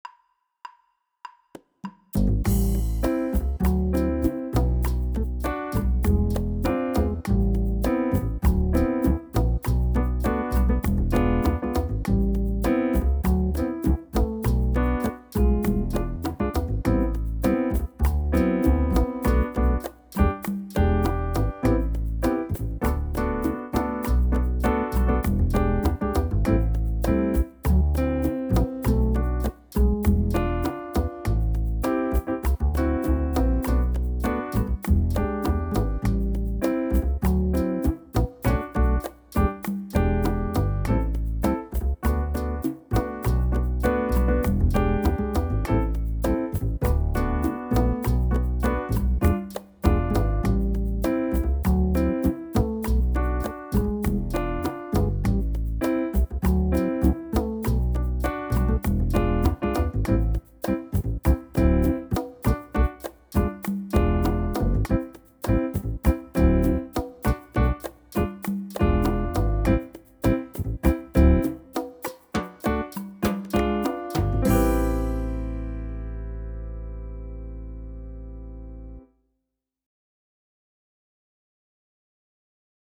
PLAYBACK JAMS
Salsa Groove in hm-E-am-D